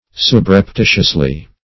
-- Sub`rep*ti"tious*ly , adv.
subreptitiously.mp3